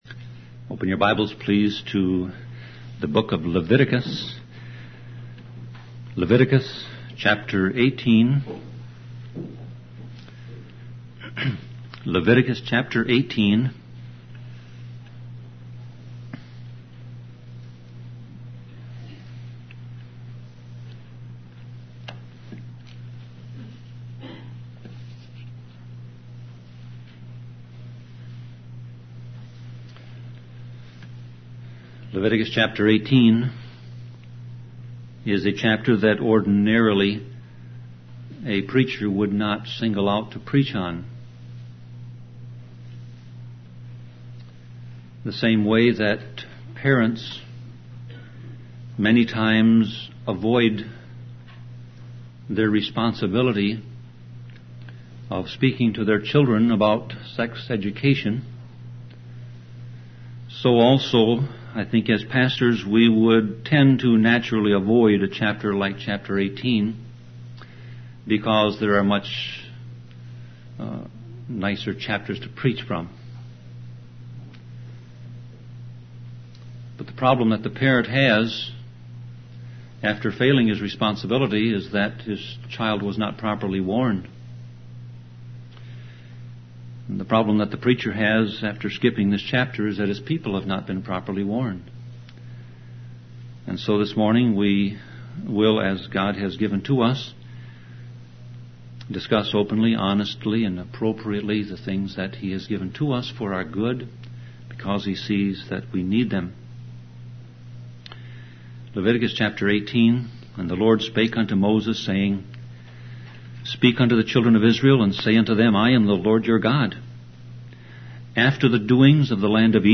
Sermon Audio Passage: Leviticus 18 Service Type